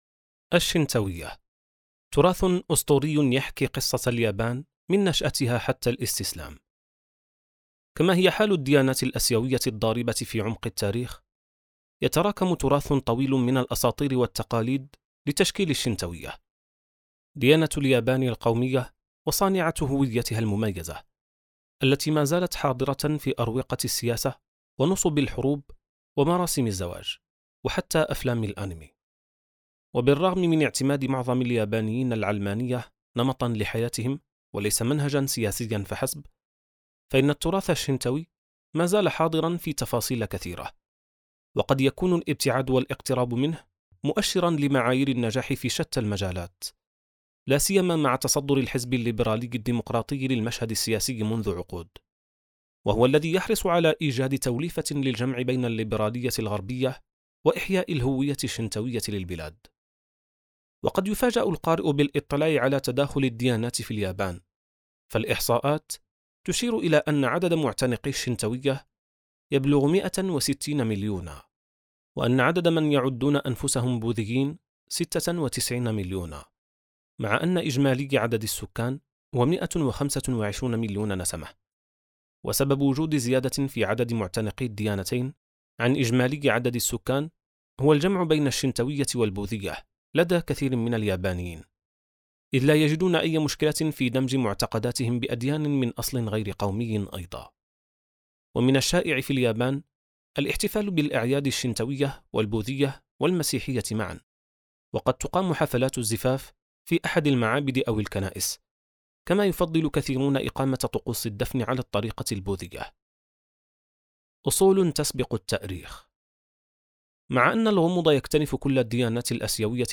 كتاب صوتي | خارطة الطوائف (419): الشنتوية • السبيل